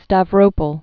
(stăv-rōpəl, stävrə-pəl)